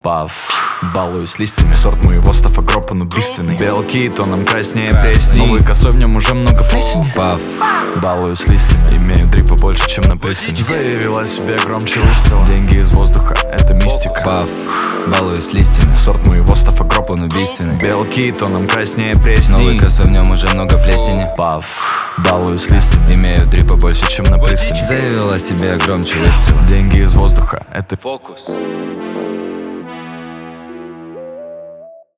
Рэп и Хип Хоп
громкие